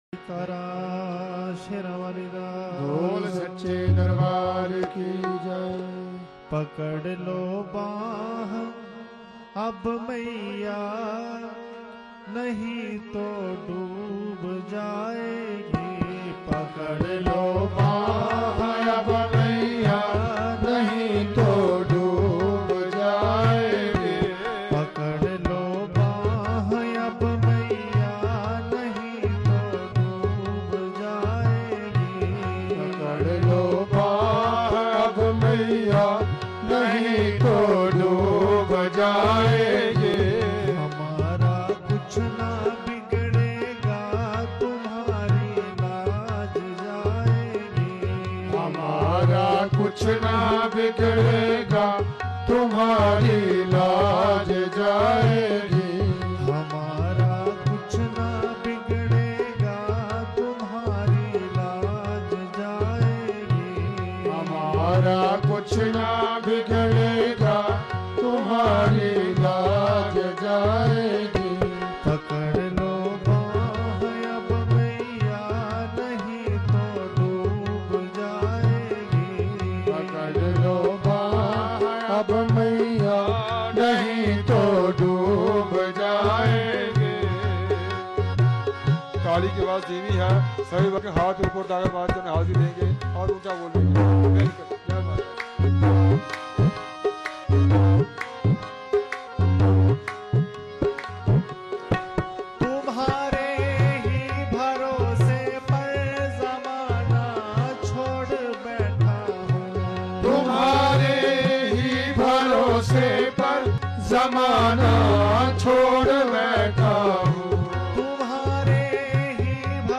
Explore bhajans from morning and evening aarti from Garbhjun Adhkuwari and Bhawan.